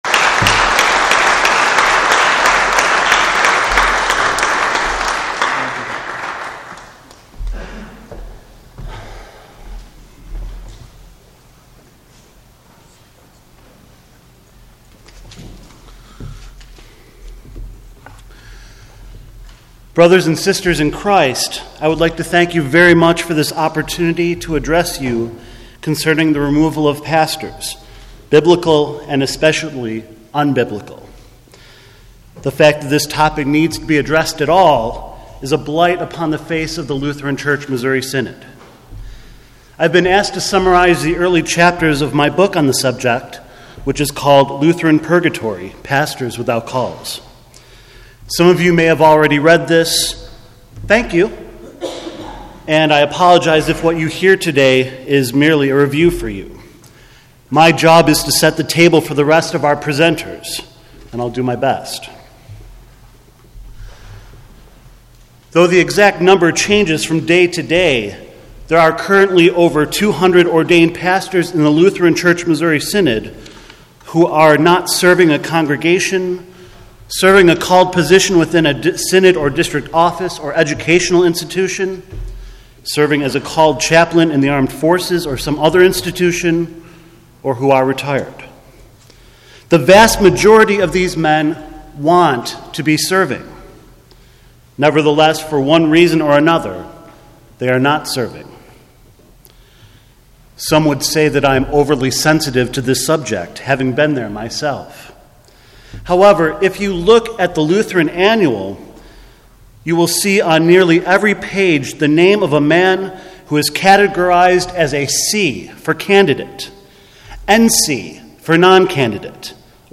I was asked by the Association of Confessing Evangelical Lutheran Congregations to present at their annual conference on this subject, and as the opening speaker I had the opportunity to set the table for the rest of the presenters.